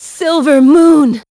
Ripine-Vox_Skill6-02.wav